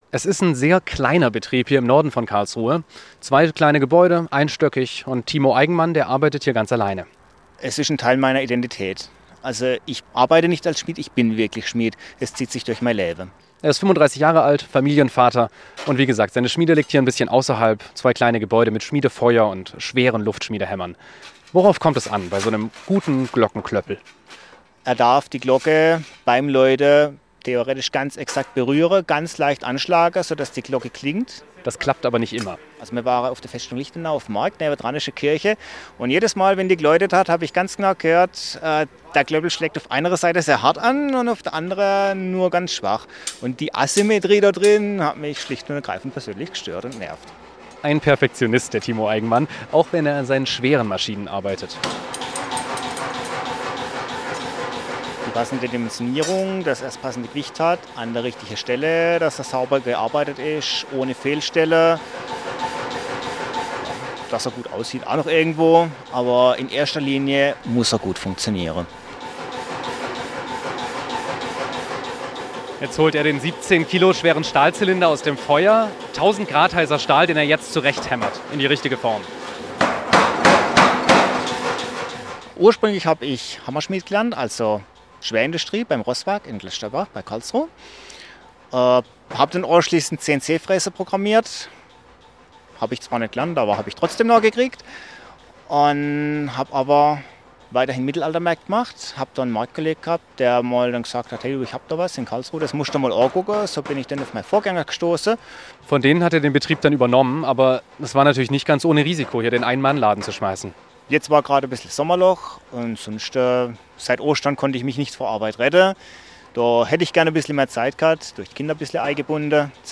Handwerkskunst Glocken-Klöppel